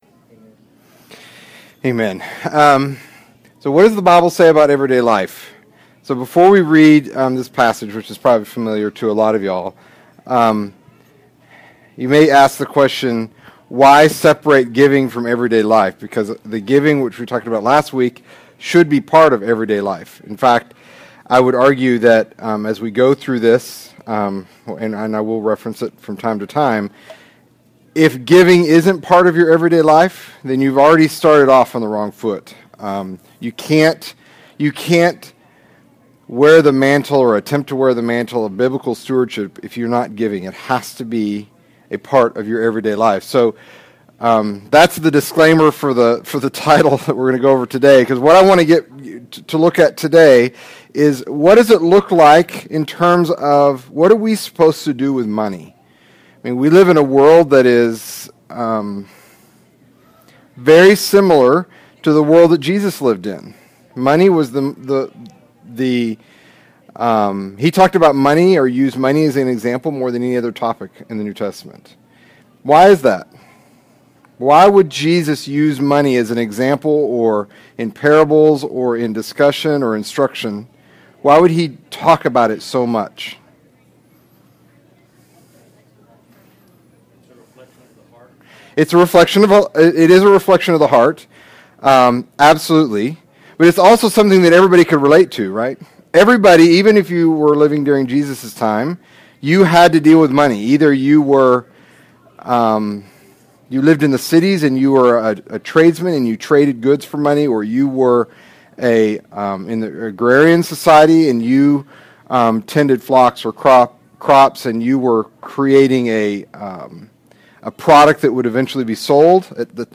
Download Files MP3 Sermon Topics: Giving ERROR: The IP key is no longer supported.